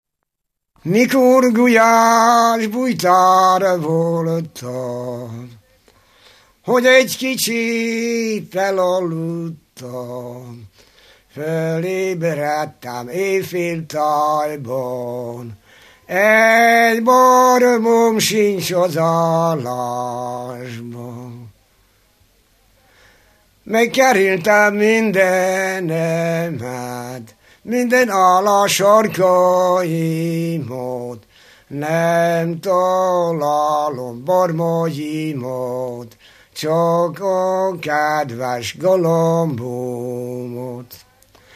Dunántúl - Somogy vm. - Varjaskér (Somogyszentpál)
ének
Stílus: 6. Duda-kanász mulattató stílus
Szótagszám: 8.8.8.8
Kadencia: 1 (1) 1 1